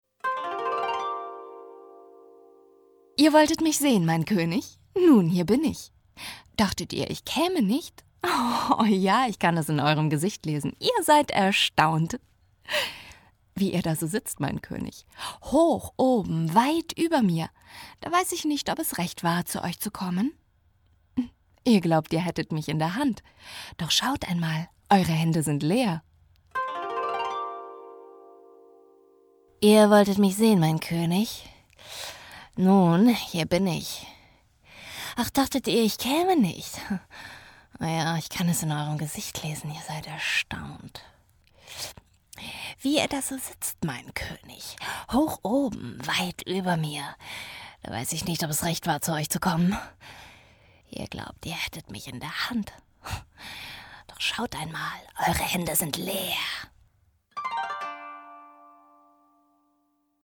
Studio-Sprecherin mit junger, frischer Stimme.
norddeutsch
Sprechprobe: Sonstiges (Muttersprache):